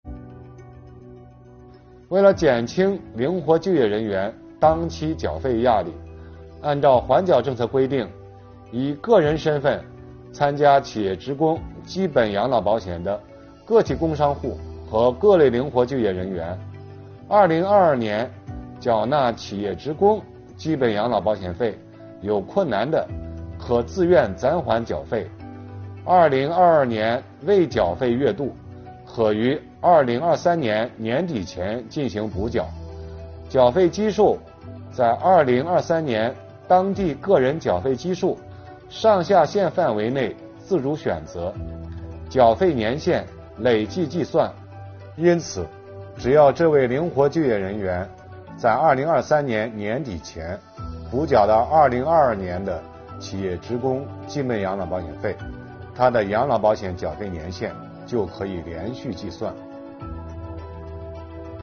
本期课程由国家税务总局社会保险费司副司长王发运担任主讲人，对公众关注的特困行业阶段性缓缴企业社保费政策问题进行讲解。